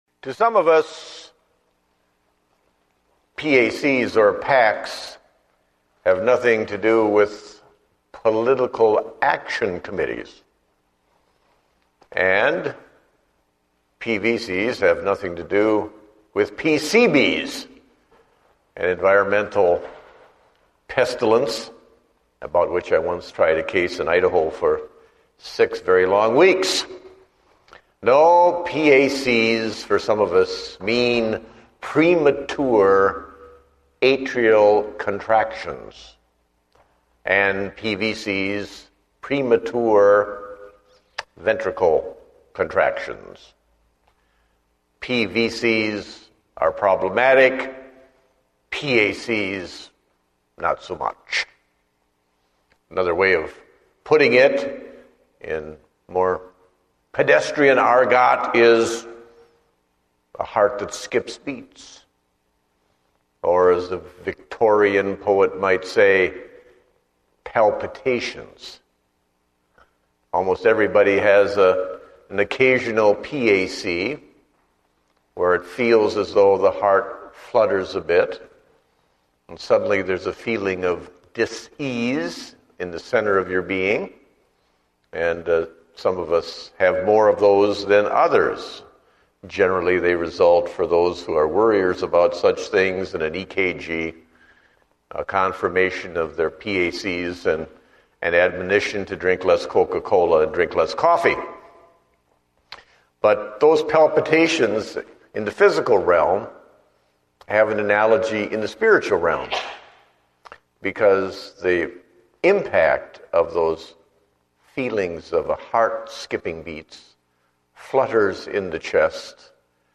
Date: August 22, 2010 (Evening Service)